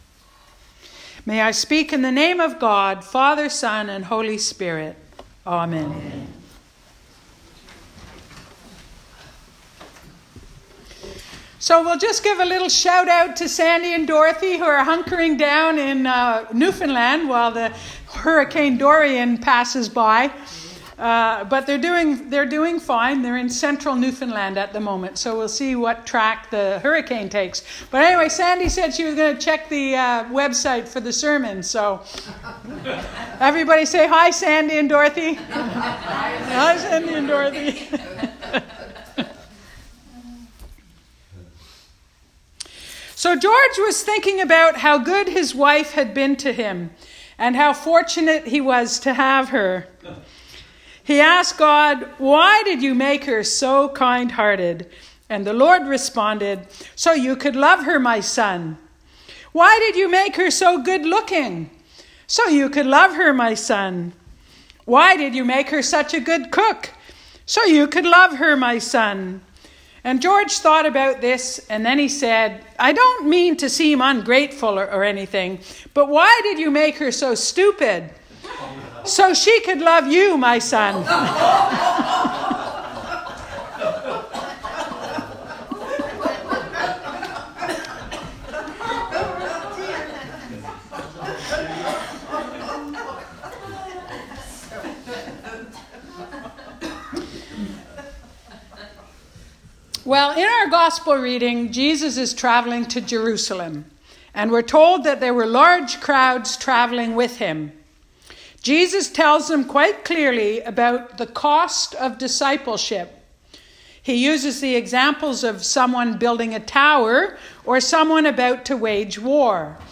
Sermons | Parish of the Valley
Recorded at St John, Tramore